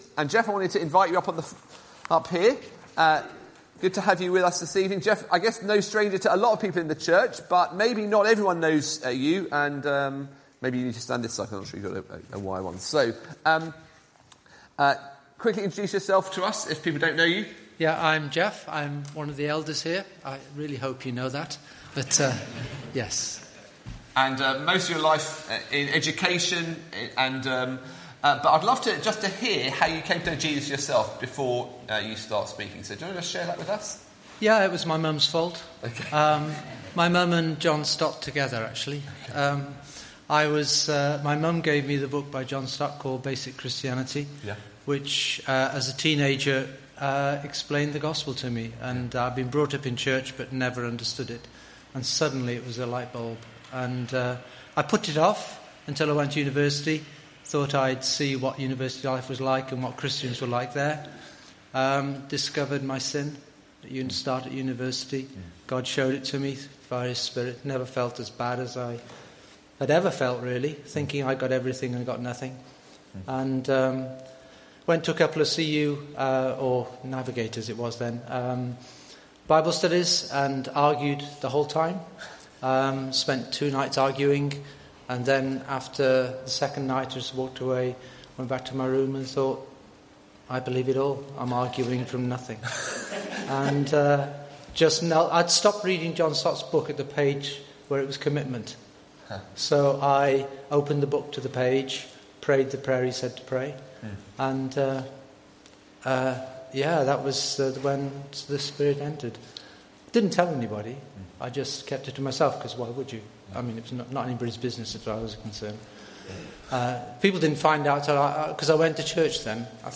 Colossians 3:12-13; 12 May 2024, Evening Service. Sermon Series: Putting on the life of Christ together https